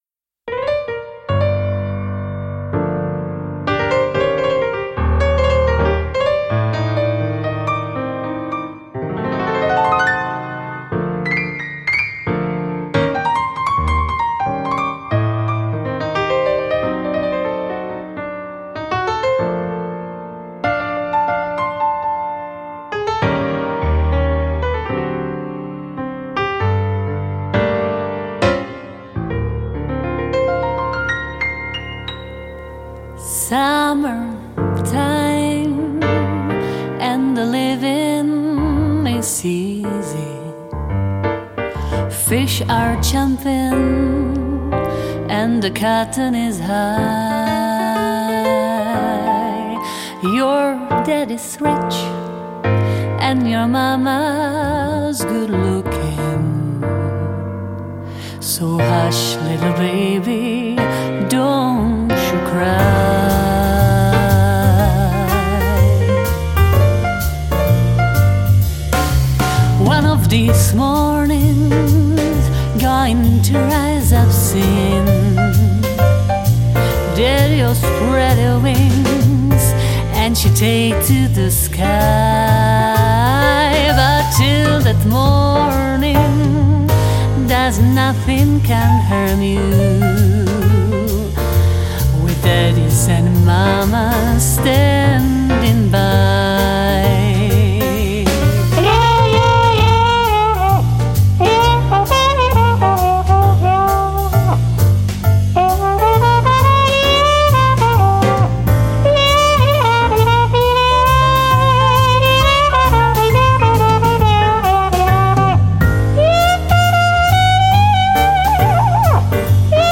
Jazz & Swing